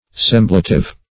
Search Result for " semblative" : The Collaborative International Dictionary of English v.0.48: Semblative \Sem"bla*tive\, a. Resembling.